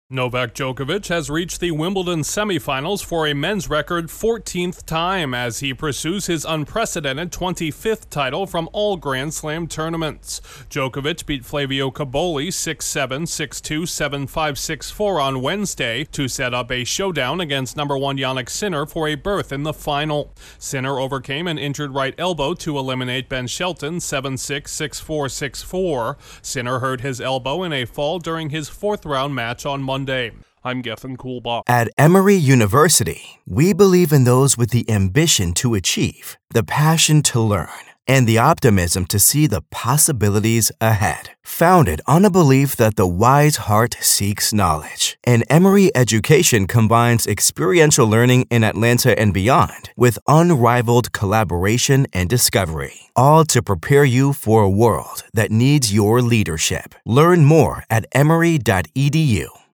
A tennis icon continues to rewrite the history books at the All England Club. Correspondent